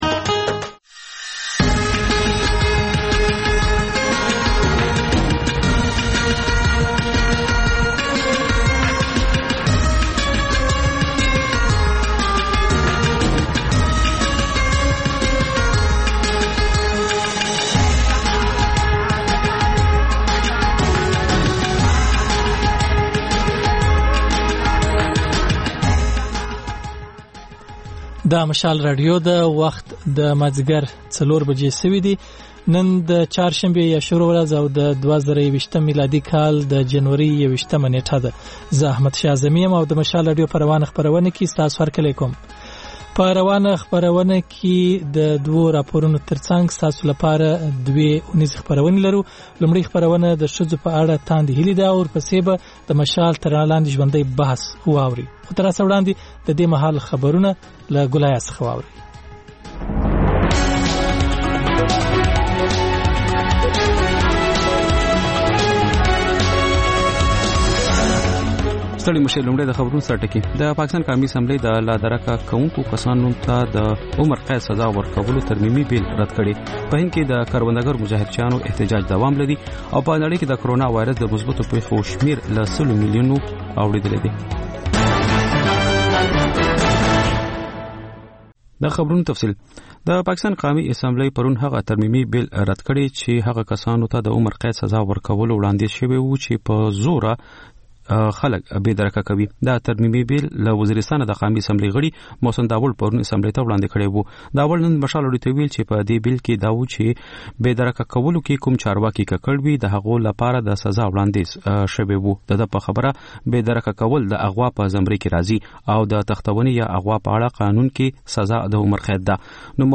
د مشال راډیو مازیګرنۍ خپرونه. د خپرونې پیل له خبرونو کېږي، بیا ورپسې رپورټونه خپرېږي. ورسره اوونیزه خپرونه/خپرونې هم خپرېږي.